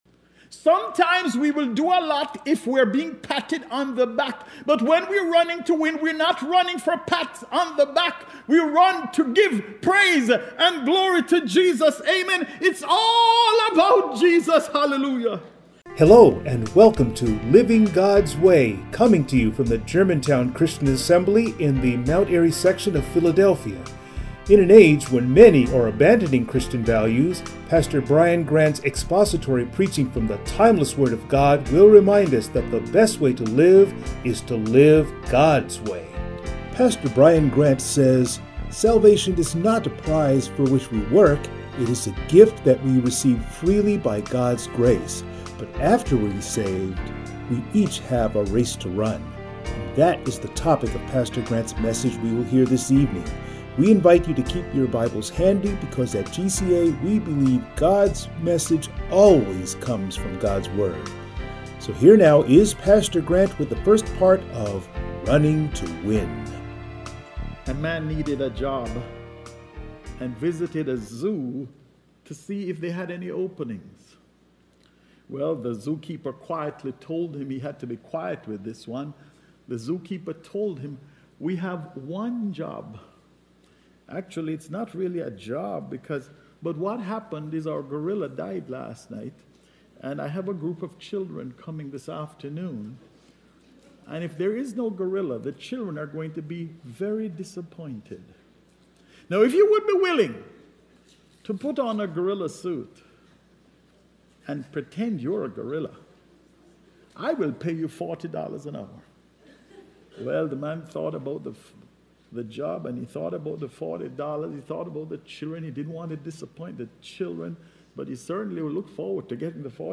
Passage: 2 Chronicles 14 Service Type: Sunday Morning